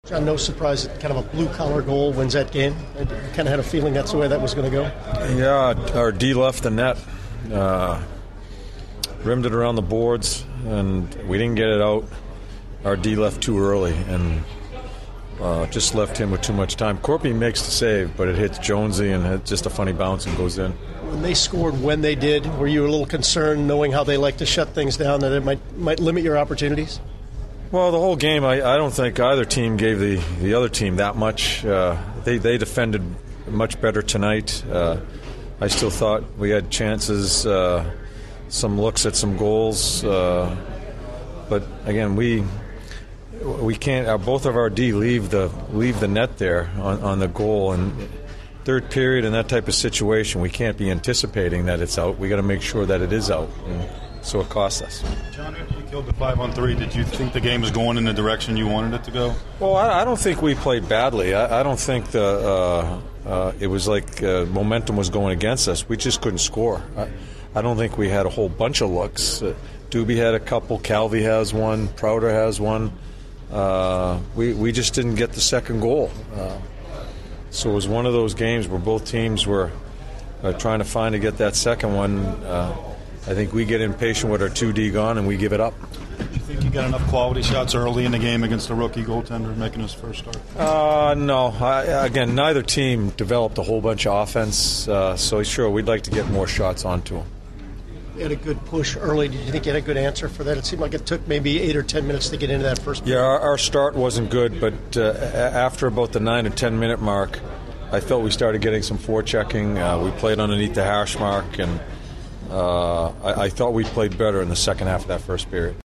John Tortorella Post-Game 03/20/16
An episode by CBJ Interviews